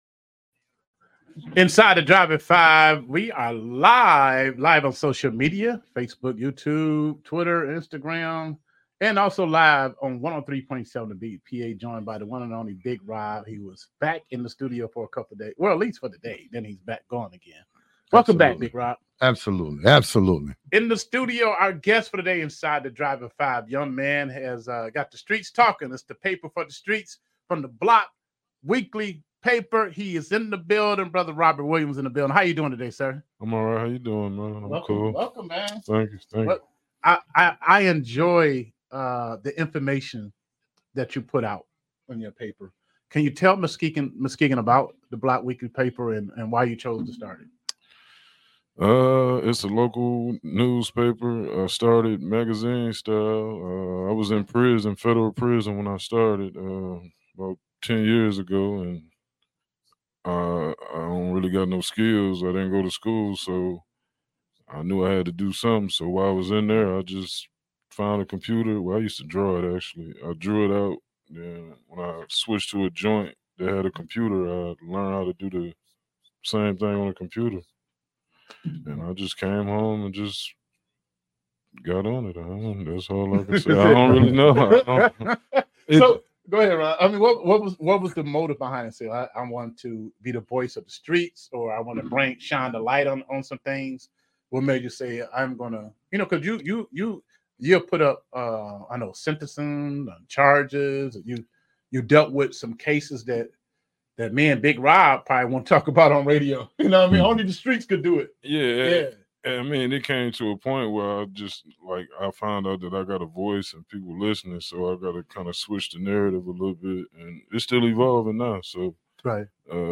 Interview with the Block Weekly Report